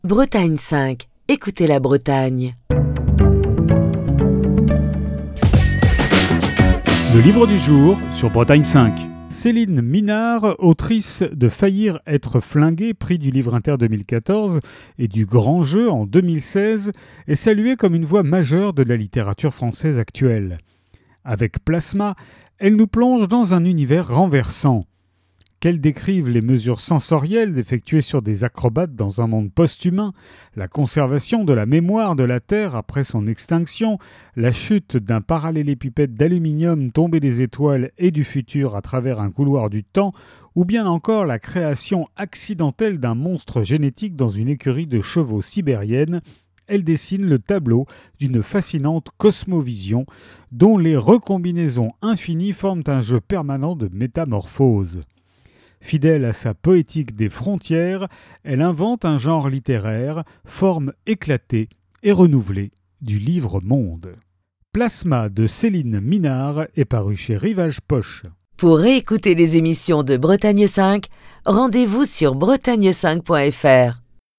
Chronique du 19 décembre 2023.